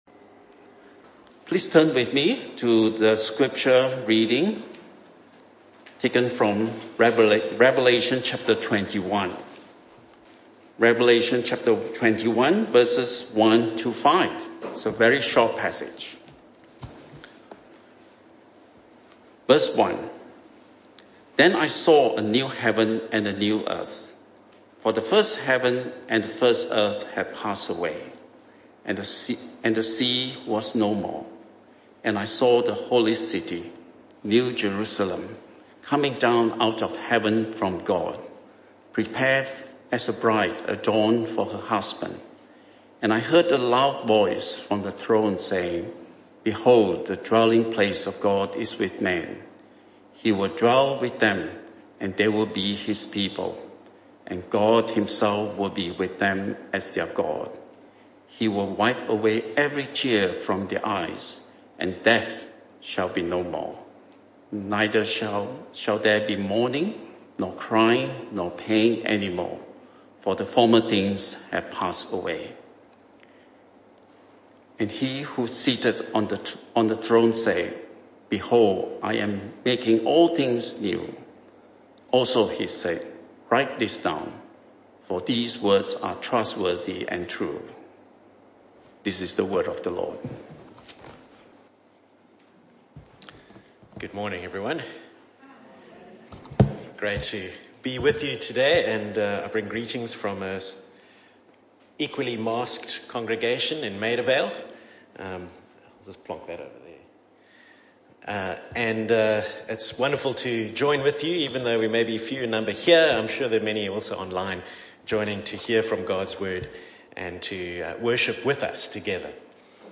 This talk was a one-off talk in the AM Service.